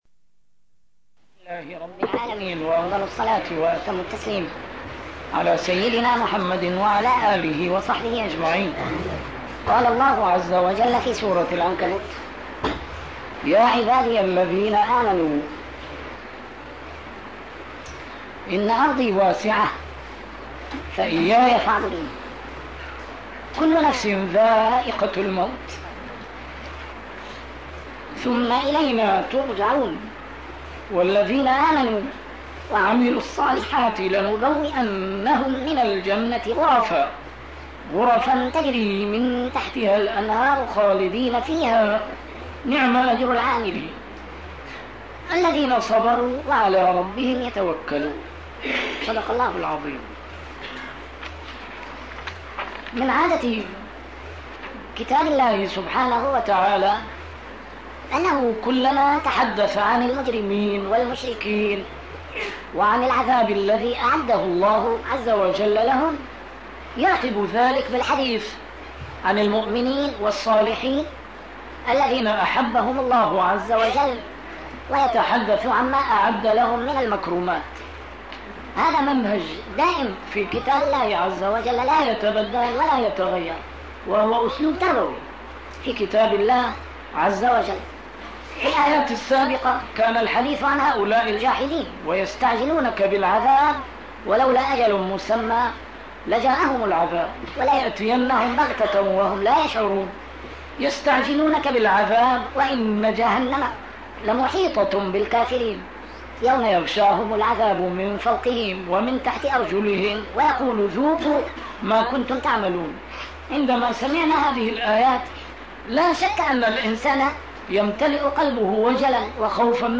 A MARTYR SCHOLAR: IMAM MUHAMMAD SAEED RAMADAN AL-BOUTI - الدروس العلمية - تفسير القرآن الكريم - تسجيل قديم - الدرس 308: العنكبوت 56-57